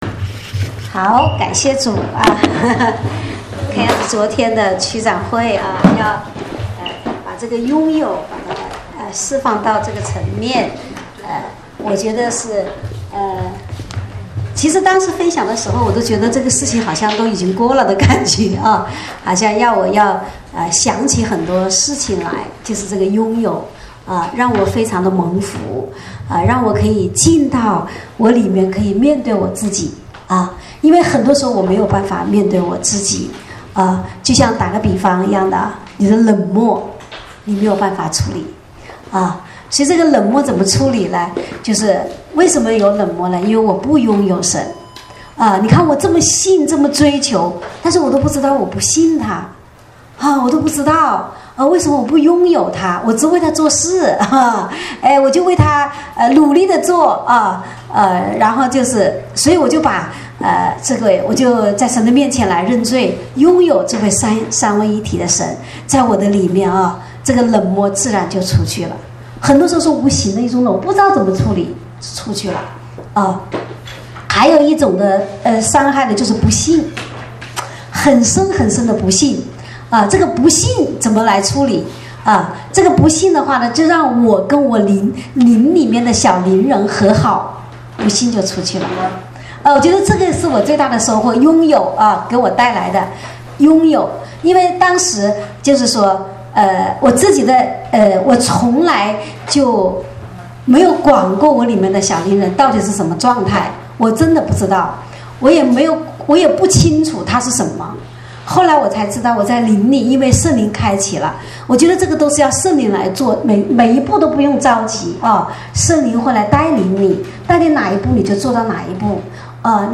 正在播放：--主日恩膏聚会录音（2014-12-28）